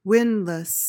PRONUNCIATION: (WIHND-luhs) MEANING: noun: A device for lifting or hauling, using a rope or cable wound around a cylinder. verb tr.: To extract, lift, or bring forth with deliberate, steady effort.